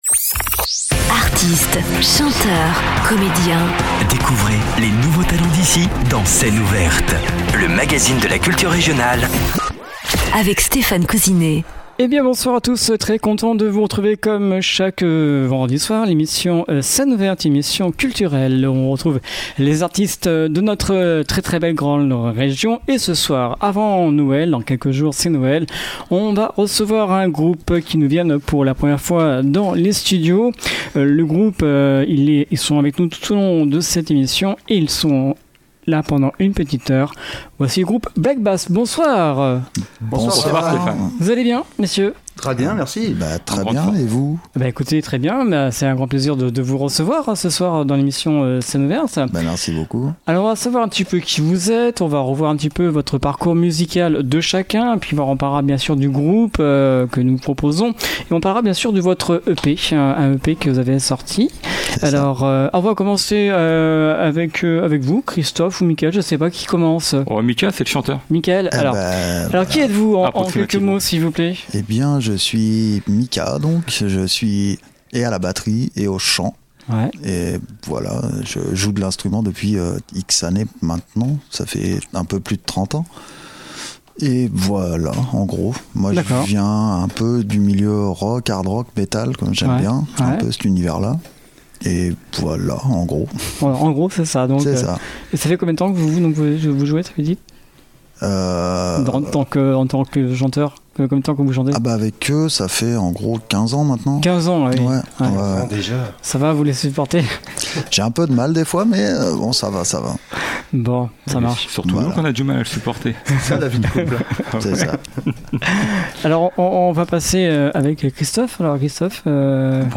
batteur et chanteur lead
guitariste chœurs
bassiste chœurs
» dans le style rock approximatif en français dans le texte.